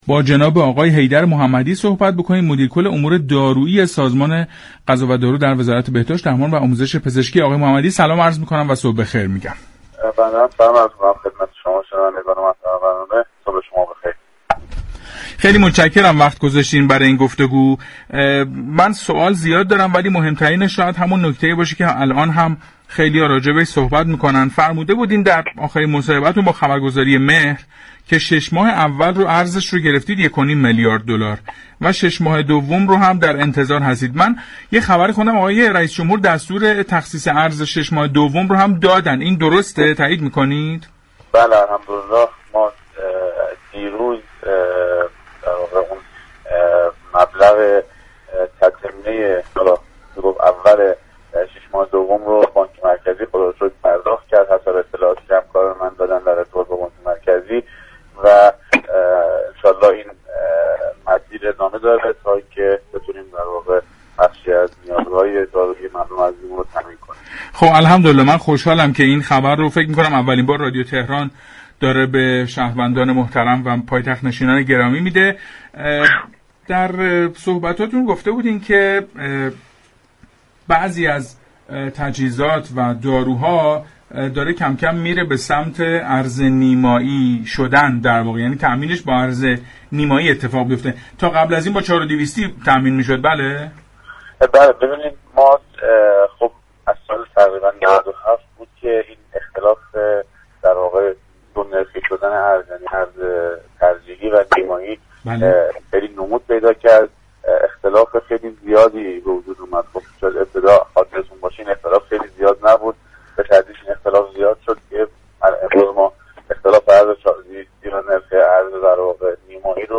در گفتگو با برنامه پارك شهر رادیو تهران